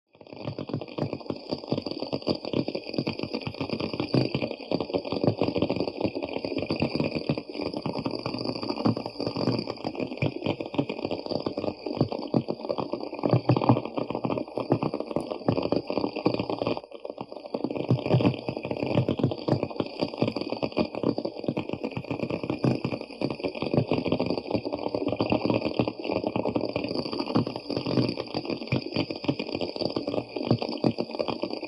5. Крабы копошатся